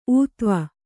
♪ ūtva